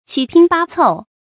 七拼八湊 注音： ㄑㄧ ㄆㄧㄣ ㄅㄚ ㄘㄡˋ 讀音讀法： 意思解釋： 指把零碎的東西拼湊起來。